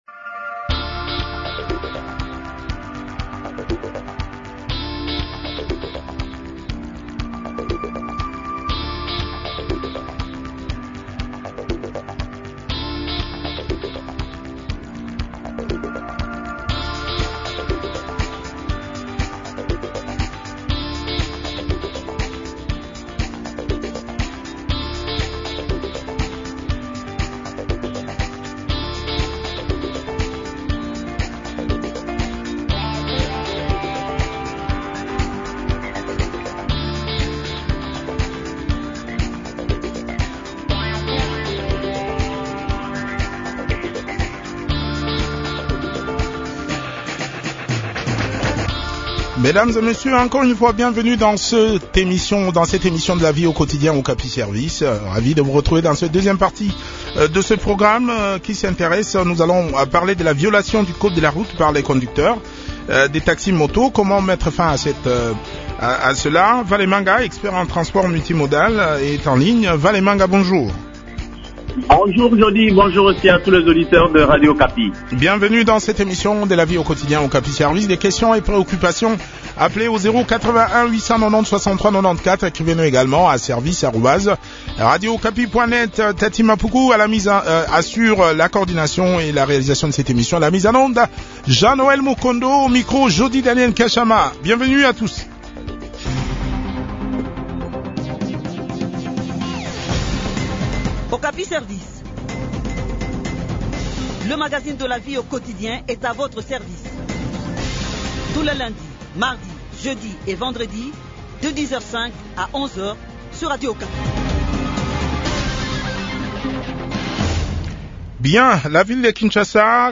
expert en transport multimodal.